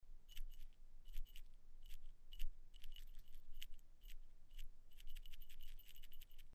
Sound recordings of original pellet bells from the cemeteries Radvaň nad Dunajom-Žitava I (Žitavská Tôň), Holiare and Skalika, SK.
Original sound of Avar pellet bells from the cemeteries
Sound recording of original pellet bell_2133_Radvaň_nad_Dunajom_Žitava_I_grave_10 0.1 MB